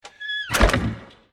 safeclose.wav